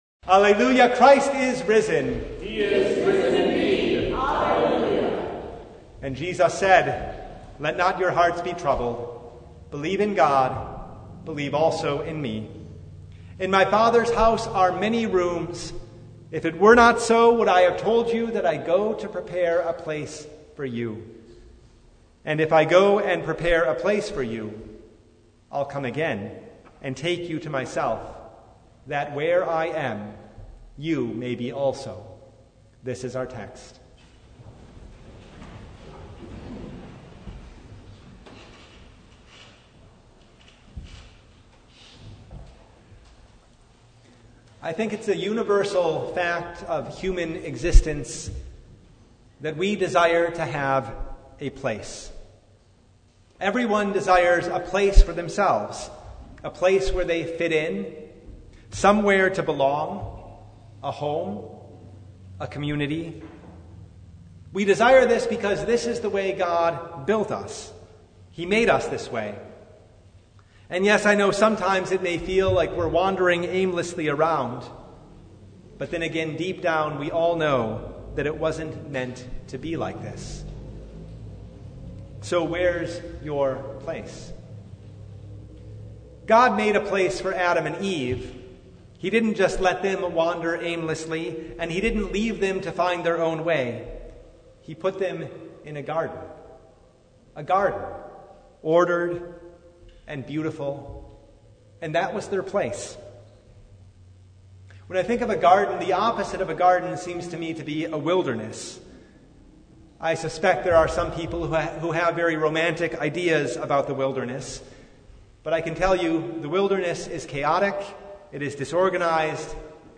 Sermon from Fifth Sunday of Easter (2023)
Passage: John 14:1-14 Service Type: Sunday Evening